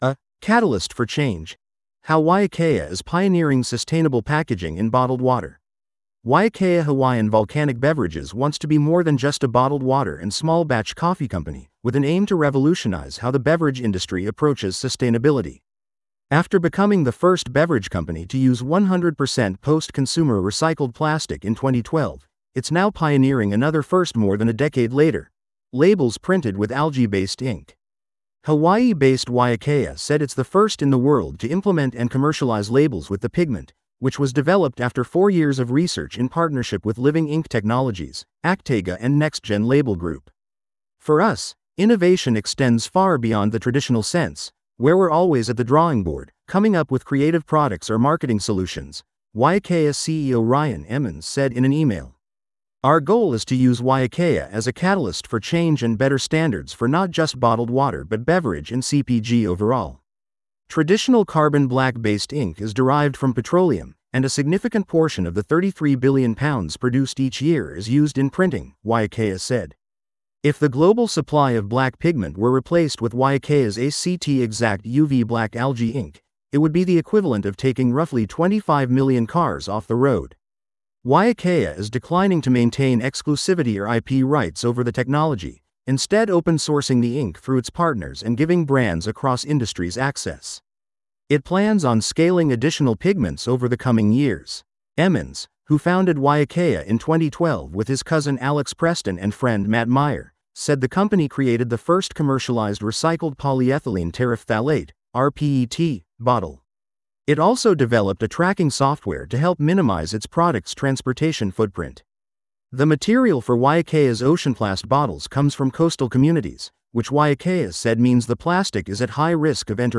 This audio is generated automatically.